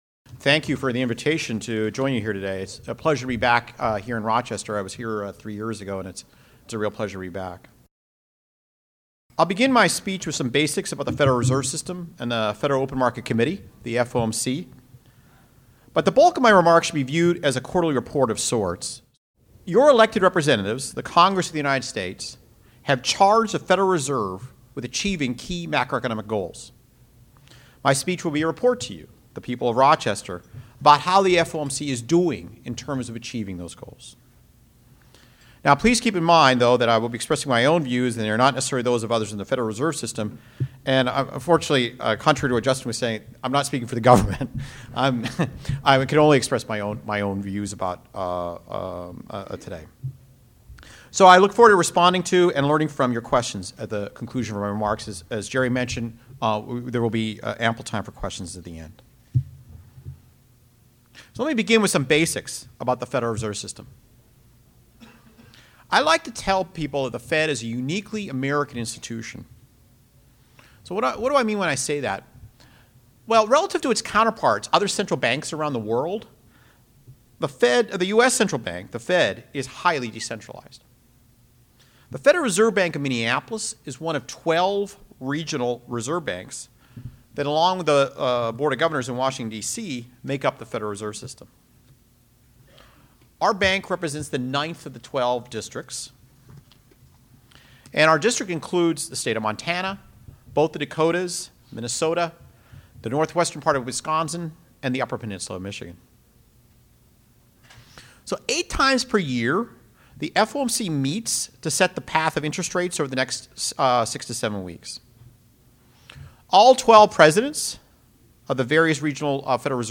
Speech (video) Media QA (video) Q&A (video) Remarks Audience QA (audio) Note * Thanks for the introduction, and thank you for the invitation to join you here today.